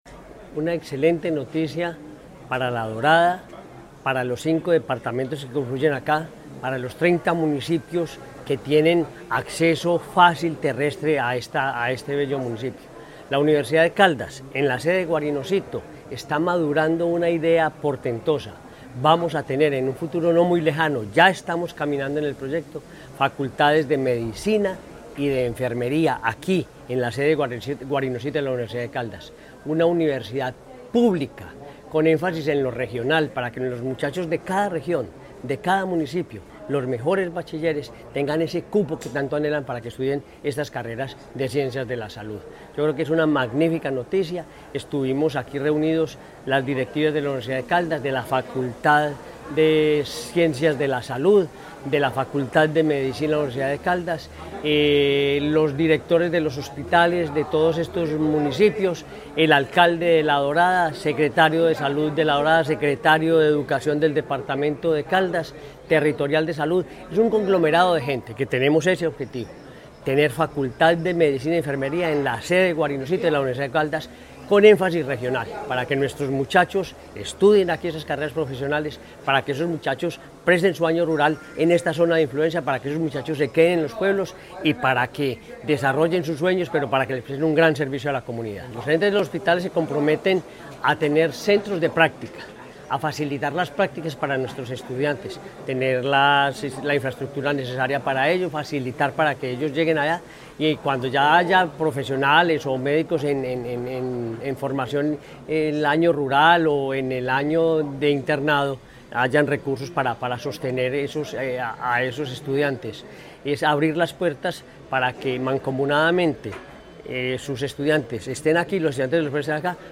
Gobernador de Caldas, Henry Gutiérrez Ángel.
Gobernador-de-Caldas-Henry-Gutierrez-universidad-en-La-Dorada-1.mp3